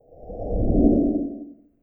effect__road_loop.wav